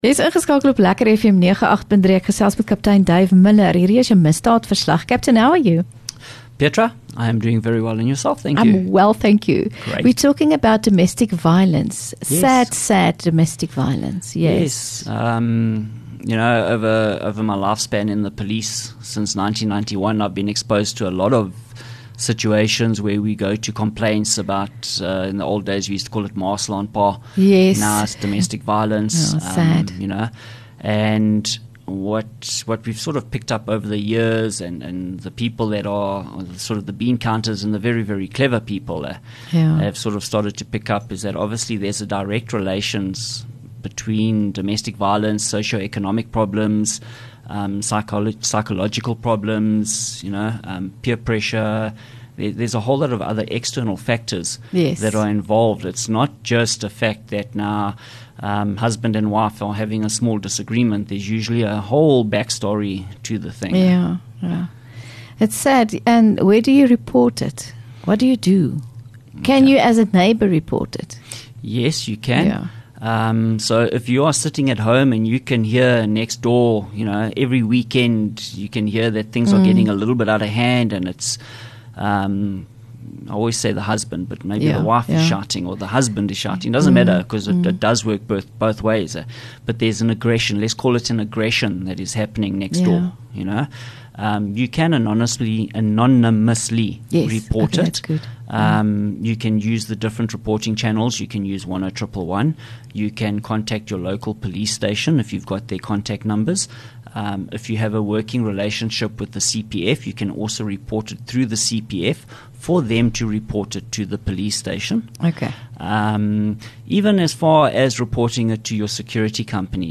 LEKKER FM | Onderhoude 5 Sep Misdaadverslag